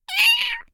cat_meow_normal3.ogg